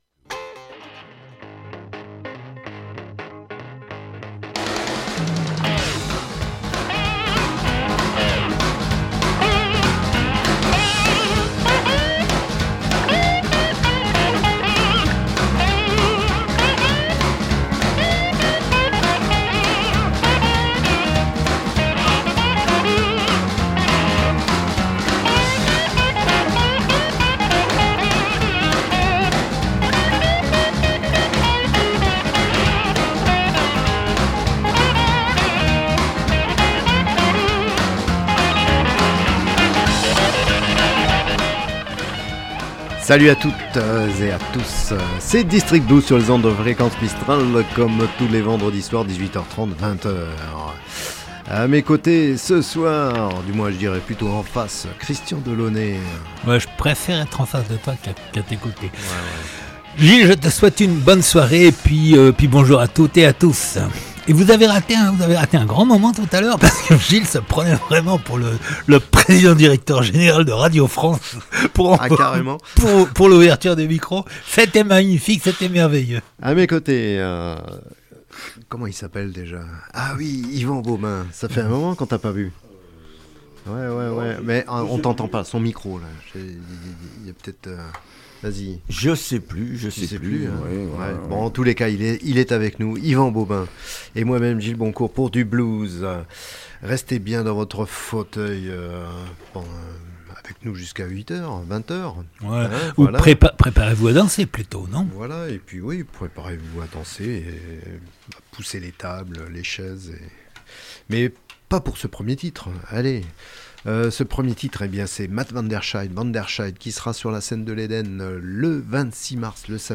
EDB du 2022-02-25.mp3 (75.88 Mo) Le blues sous toutes ses formes, le blues sous toutes ses faces, voilà le credo d’Eden District Blues, qu’il vienne de Chicago, de Milan, du Texas ou de Toulouse, qu’il soit roots, swamp, rock ou du delta…
« DISTRICT BLUES », une émission hebdomadaire, tous les vendredis à 18h30 et rediffusée le mercredi à 23h00 .